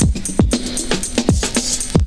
ELECTRO 13-L.wav